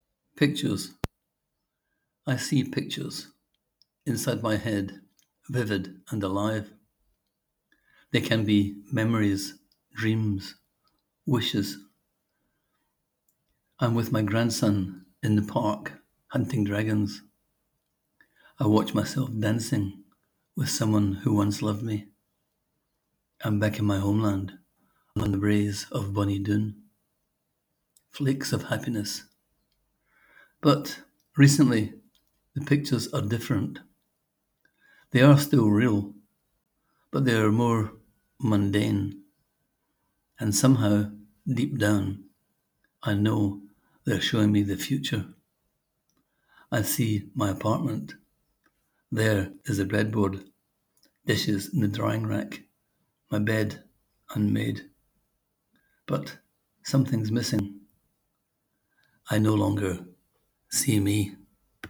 Click here to hear the author read his words:
I love your poetic audio rendition.